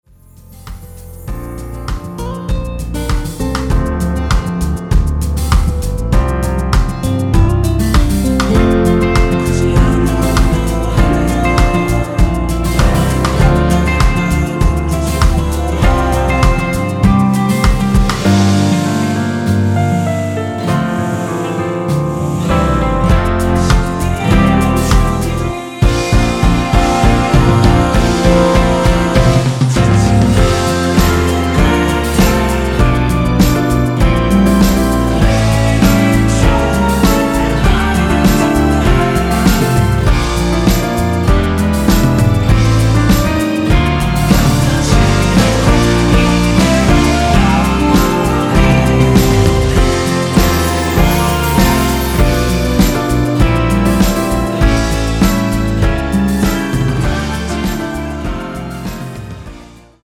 원키에서(-3)내린 코러스 포함된 MR입니다.
앞부분30초, 뒷부분30초씩 편집해서 올려 드리고 있습니다.